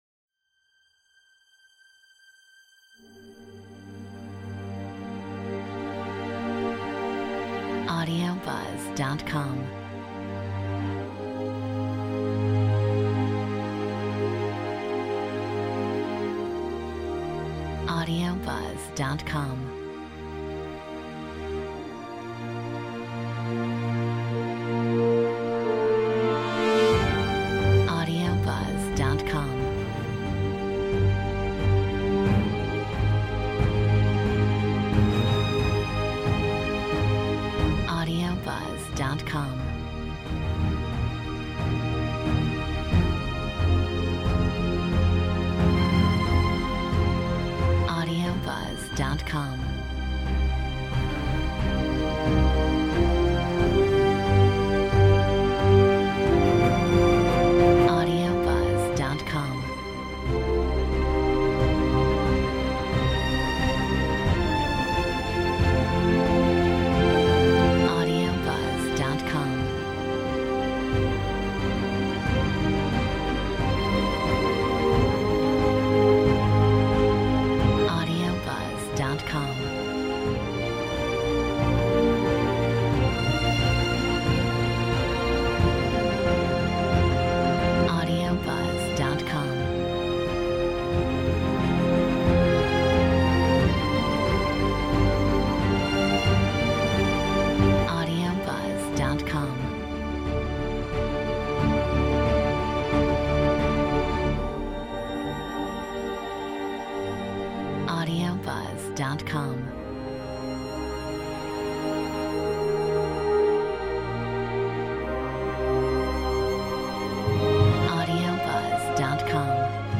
Metronome 90